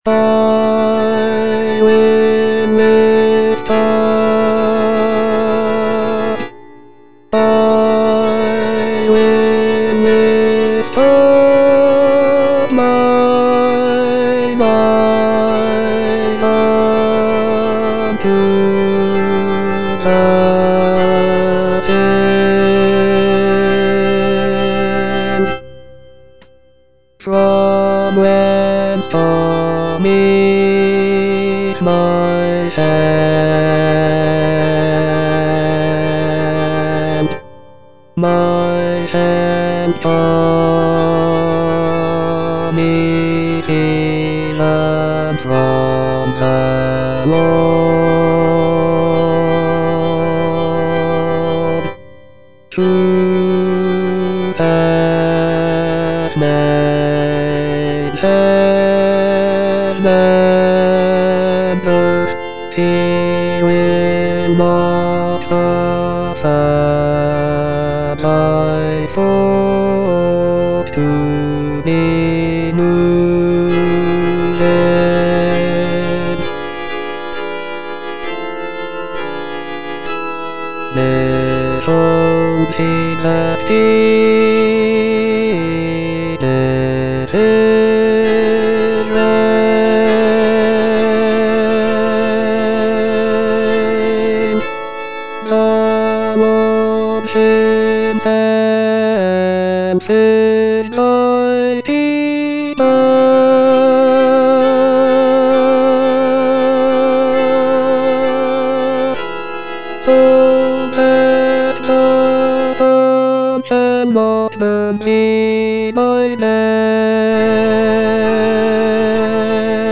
Bass I (Voice with metronome)
is a choral work